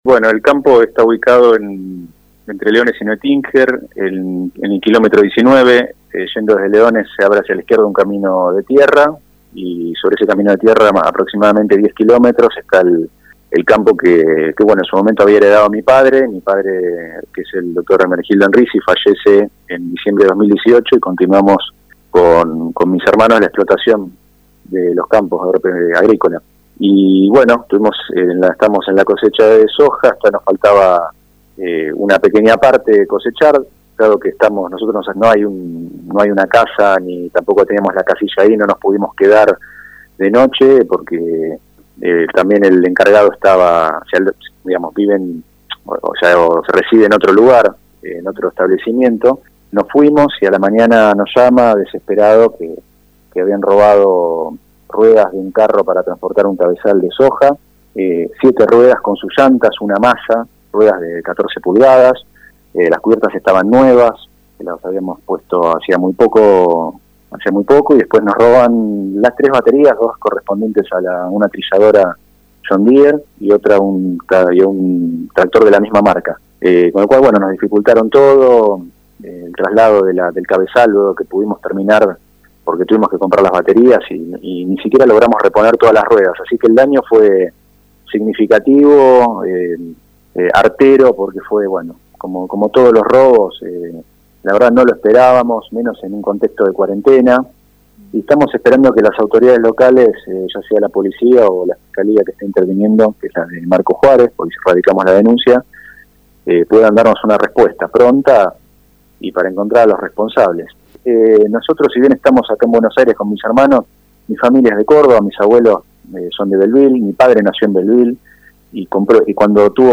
El propietario charló con La Urbana y dio detalles.